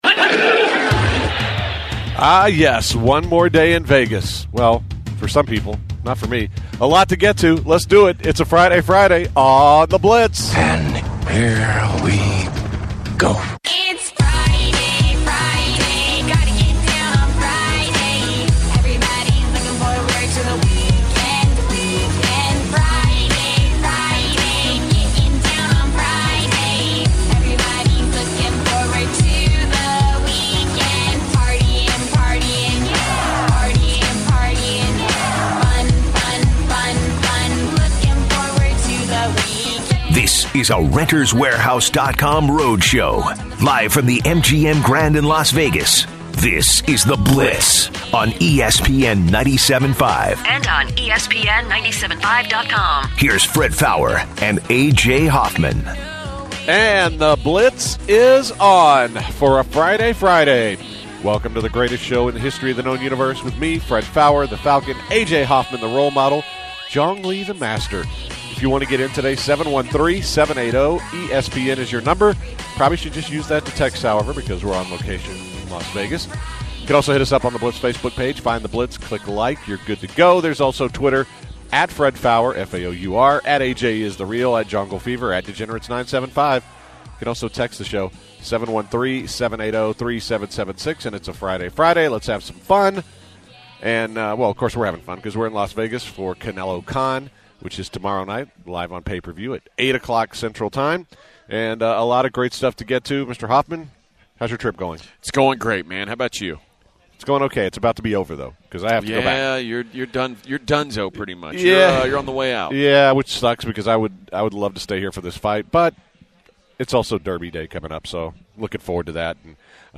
live from Las Vegas, Nevada.